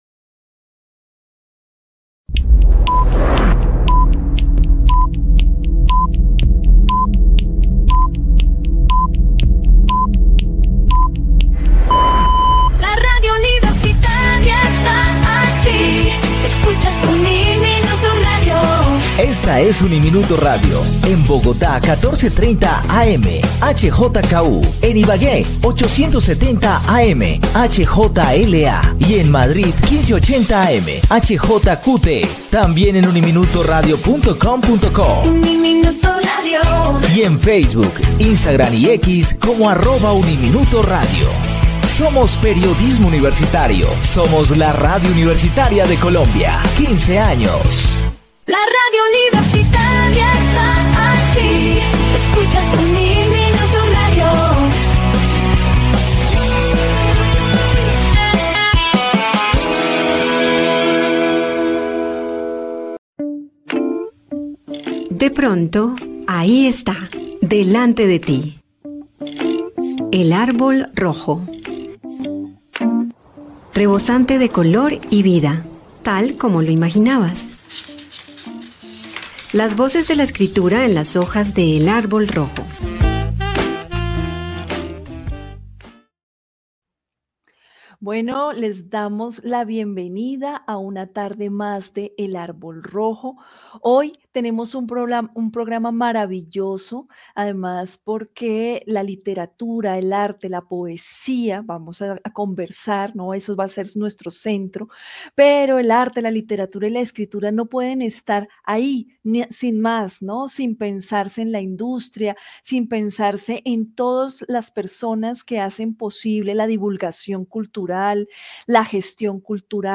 El Árbol Rojo: conversación